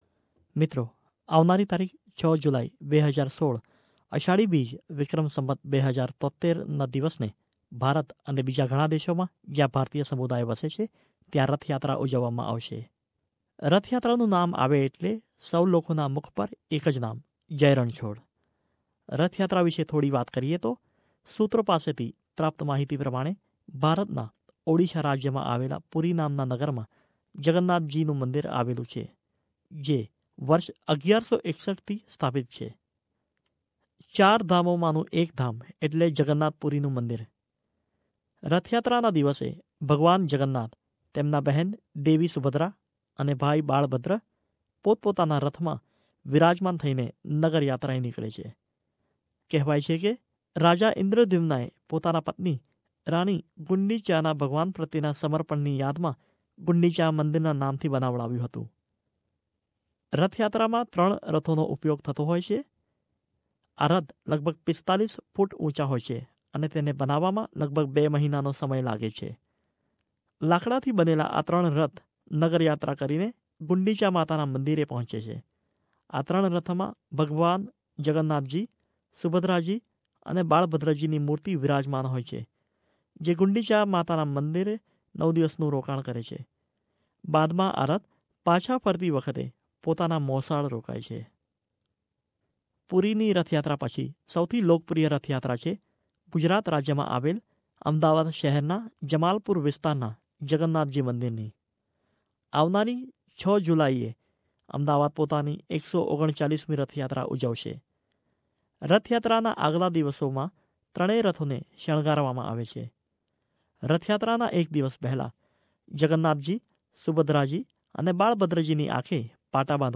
On 6th July 2016, Lord Jagannath will be on his city tour along with his sister Subhadra and brother Balbhadra to bless the disciples in their chariots. A report